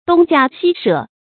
東家西舍 注音： ㄉㄨㄙ ㄐㄧㄚ ㄒㄧ ㄕㄜˋ 讀音讀法： 意思解釋： 猶言左鄰右舍。